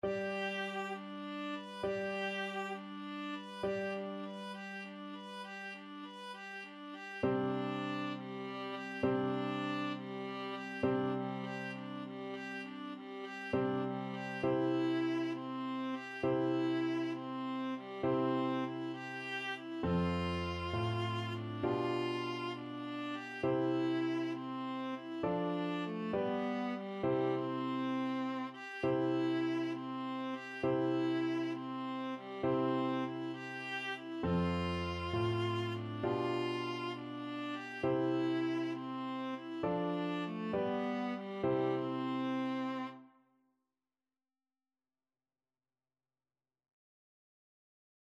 Viola
6/8 (View more 6/8 Music)
C major (Sounding Pitch) (View more C major Music for Viola )
Allegretto
Classical (View more Classical Viola Music)